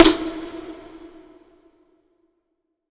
[ET] Yeezy Snare.wav